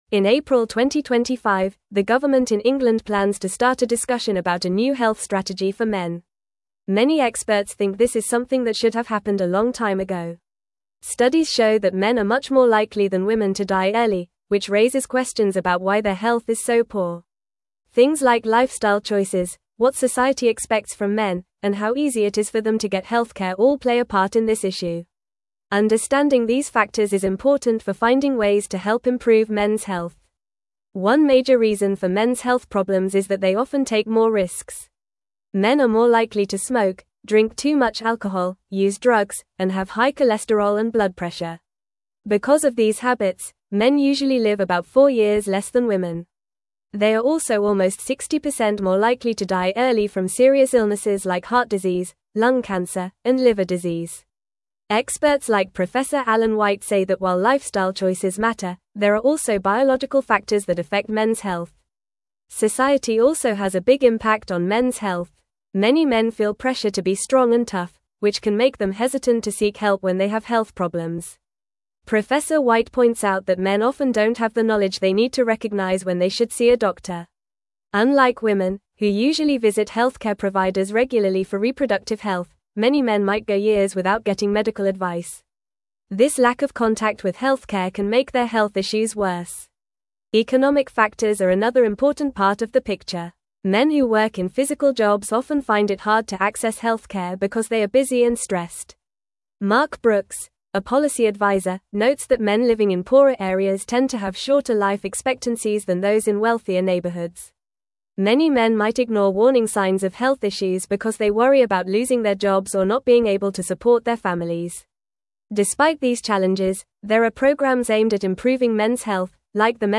Fast
English-Newsroom-Upper-Intermediate-FAST-Reading-UK-Government-Launches-Consultation-for-Mens-Health-Strategy.mp3